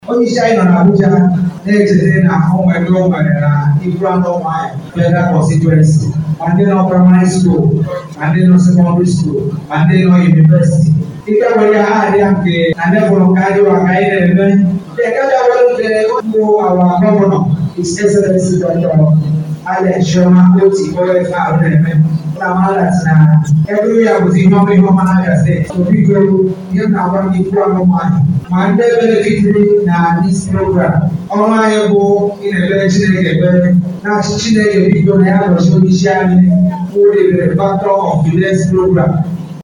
Also speaking, the Deputy Speaker of the Abia State House of Assembly Rt Hon. Austin Mereghini (Ugolee) noted that the Labour Party and its Leadership in Abia State has shown quality leadership style from top to bottom as he appreciated Aguocha for not only representing his constituents in Abuja, but bringing home the dividends of quality representation for constituents to benefit from.